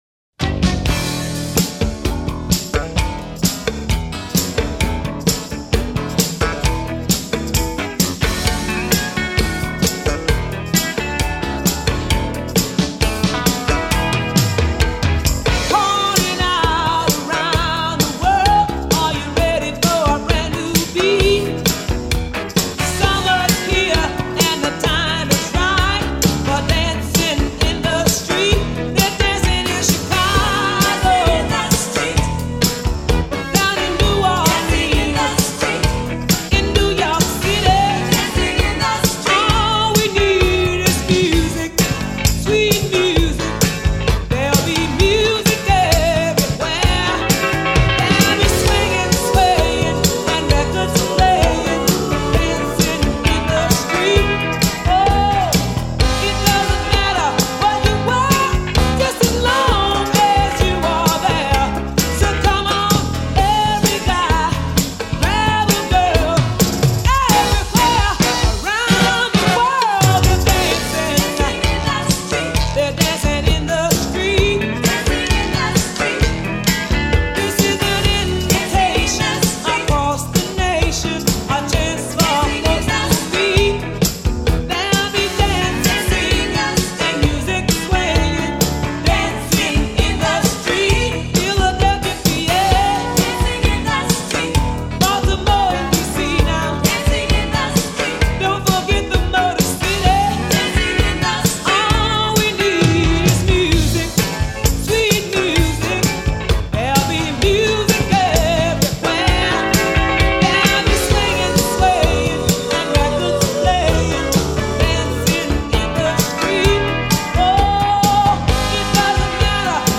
Version Slap+Cowbell